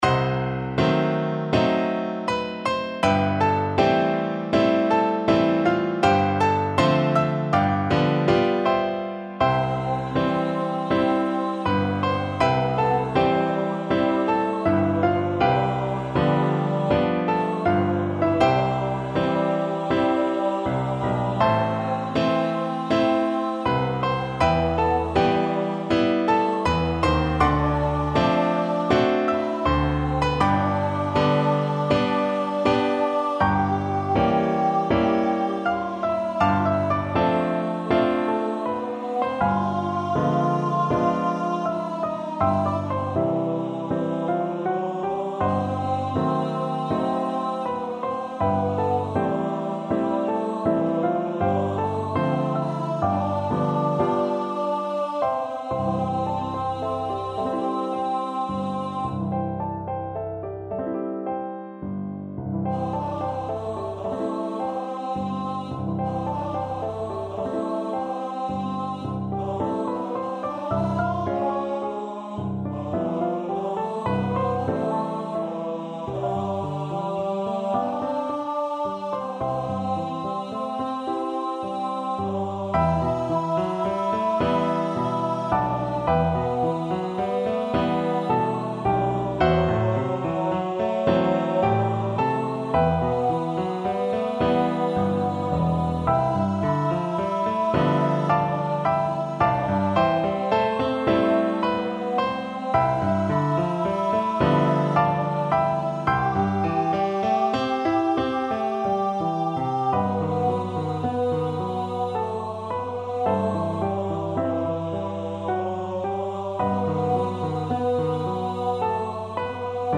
Tenor Voice
C major (Sounding Pitch) (View more C major Music for Tenor Voice )
4/4 (View more 4/4 Music)
~ = 80 Allegretto moderato, ma non troppo